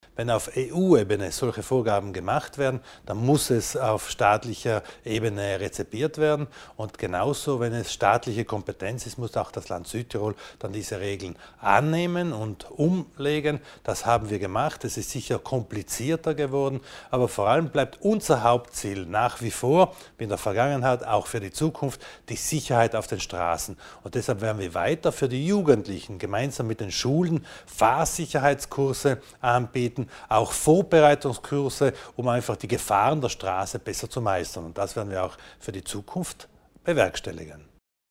Landesrat Widmann erläutert den Einsatz für mehr Sicherheit auf der Straße